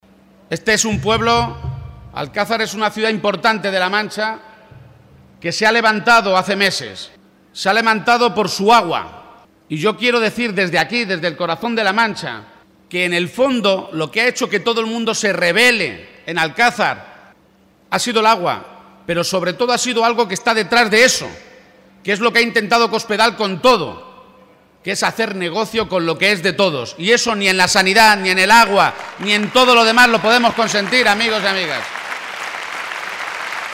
El candidato del PSOE a la Presidencia de Castilla-La Mancha, Emiliano García-Page, ha compartido esta tarde un gran acto público junto al secretario general socialista, Pedro Sánchez, en Alcázar de San Juan, ante más de 1.500 personas, y allí ha advertido una vez más que «el contador sigue en marcha, y va marcha atrás, como la región estos cuatro años, y seguimos sin conocer el programa electoral de Cospedal».